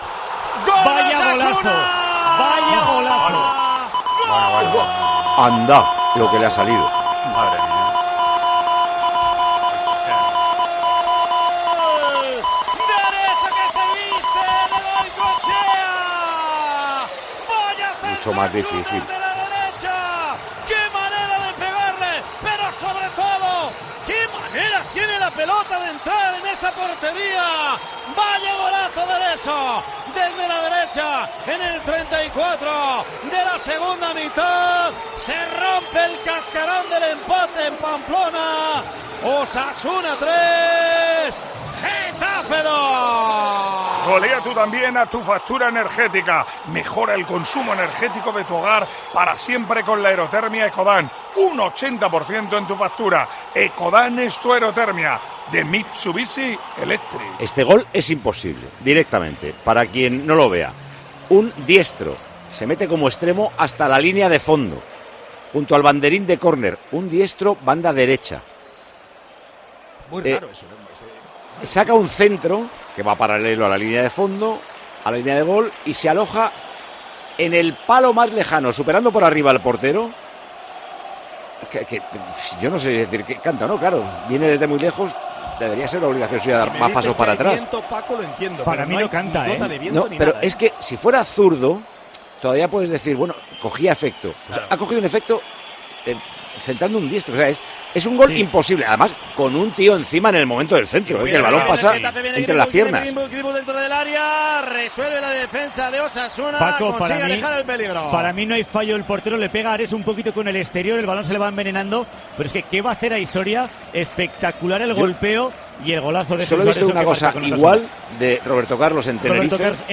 Narración en Tiempo de Juego del golazo de Jesús Areso contra el Getafe